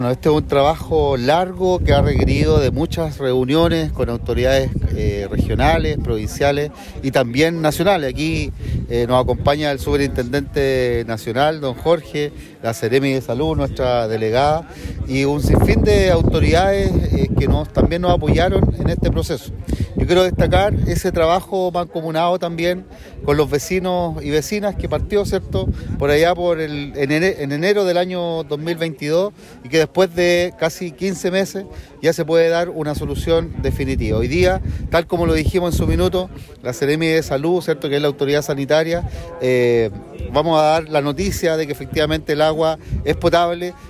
Mientras que el alcalde de Los Vilos, Christian Gross sostuvo que
Agua-Pichidangui-Cuna-02-Alcalde-Los-Vilos-Christian-Gross-online-audio-converter.com_.mp3